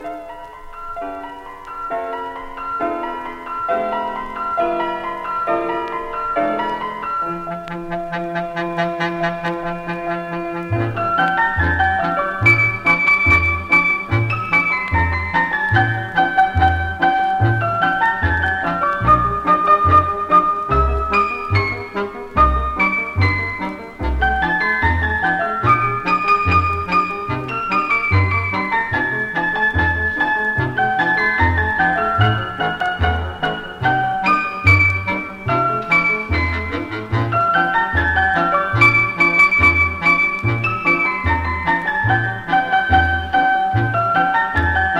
Jazz, Pop, Easy Listening　USA　12inchレコード　33rpm　Mono